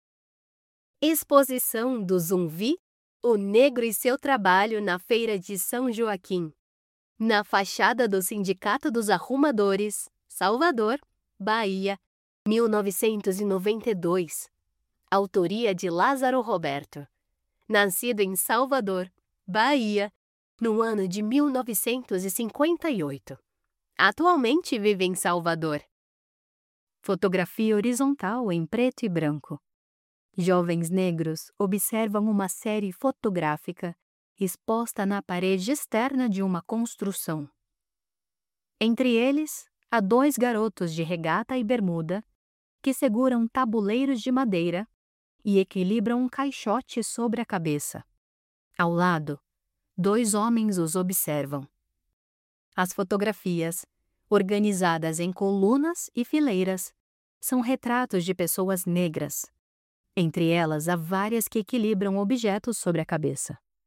Caderno de mediação cultural - Zumví Arquivo Afro Fotográfico | Audiodescrições - Imagem 5 - Instituto Moreira Salles